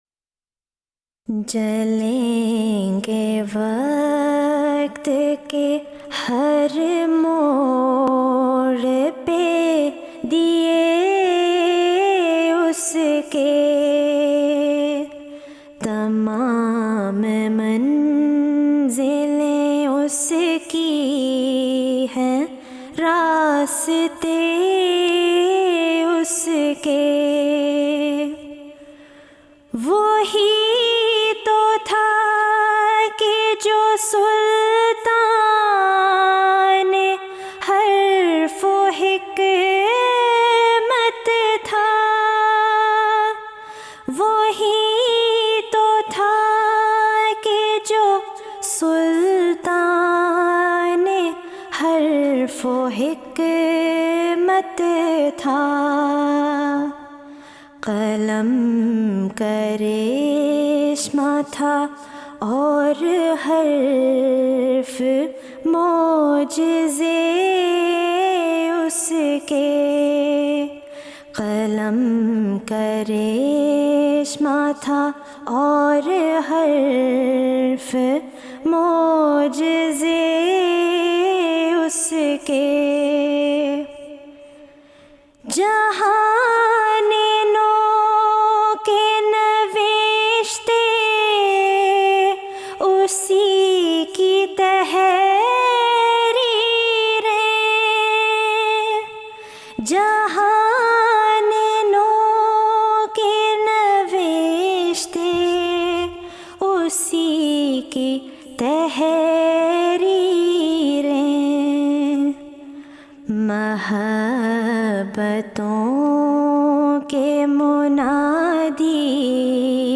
نظمیں (Urdu Poems)
جلسہ سالانہ یوکے ۲۰۱۳ء Jalsa Salana UK 2013